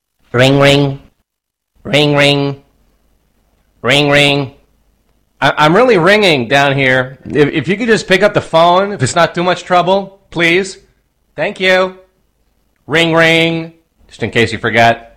File Type : Funny ringtones